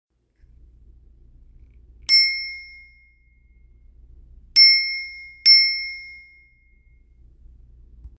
ding!
bell Ding small sound effect free sound royalty free Sound Effects